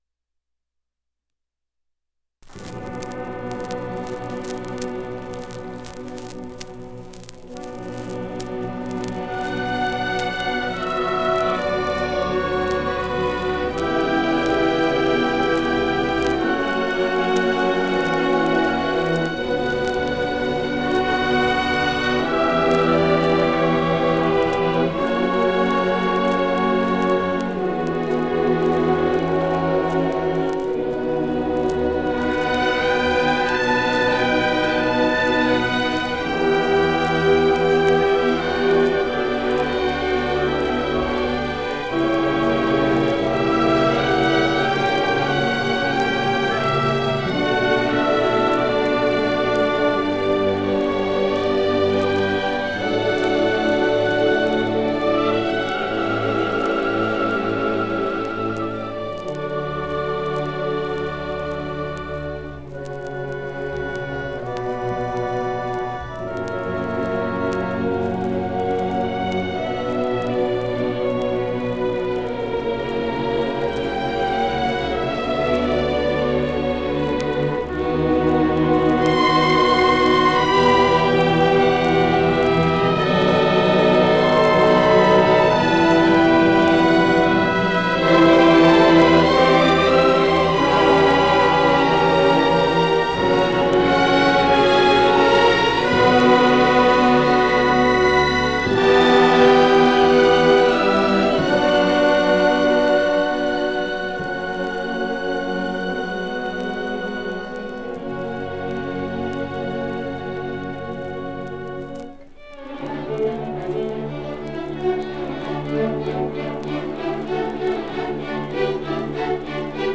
1957 Music in May orchestra performance recording · Digital Exhibits · heritage
30f6cba15e21ec8ab5a5f556e858a946023f5361.mp3 Title 1957 Music in May orchestra performance recording Description An audio recording of the 1957 Music in May orchestra performance at Pacific University.
It brings outstanding high school music students together on the university campus for several days of lessons and events, culminating in the final concert that this recording preserves.